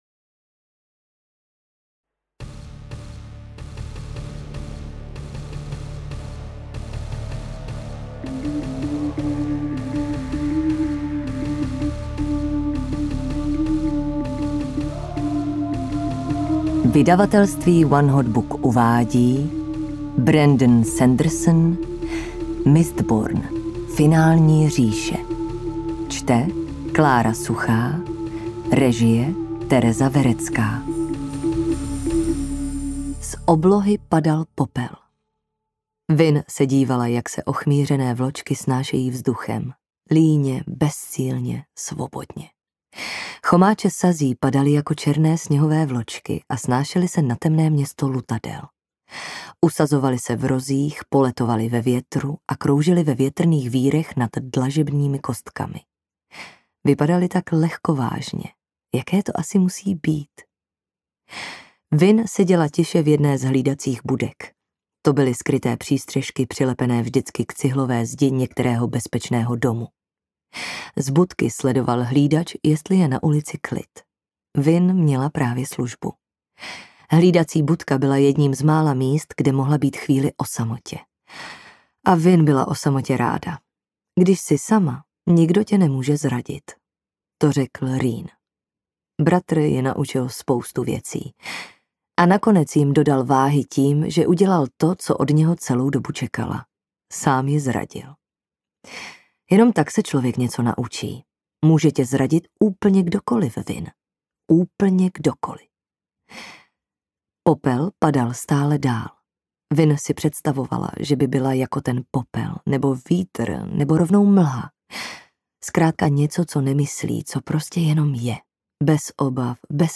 Mistborn I.: Finální říše audiokniha
Ukázka z knihy